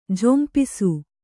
♪ jhompisu